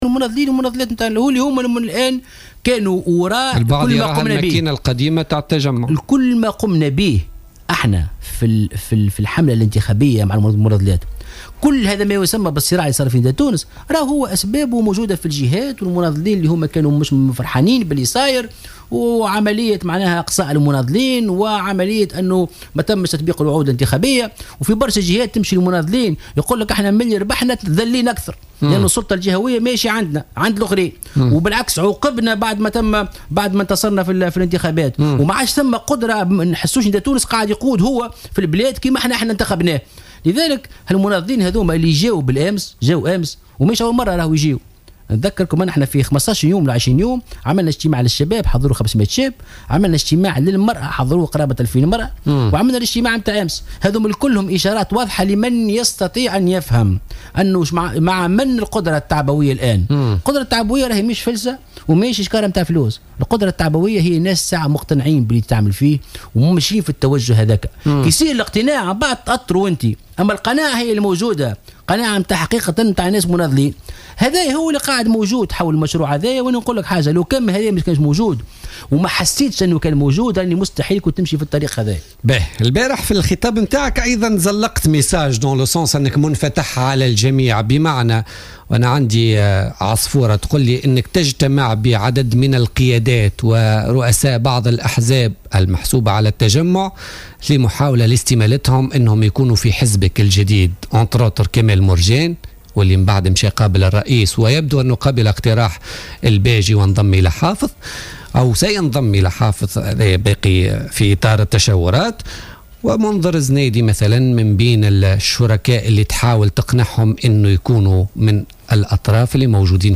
قال الأمين العام المستقيل من حزب نداء تونس محسن مرزوق ضيف بوليتيكا اليوم الإثنين 11 ديسمبر 2016 أن هناك لقاءات ومشاورات جارية بينه وبين عدد كبير من الشخصيات السياسية الدستورية في إطار دعوتهم للانضمام للمشروع الذي مازال في طور البناء.